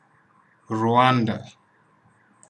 Rwanda_pronunciation.ogg.mp3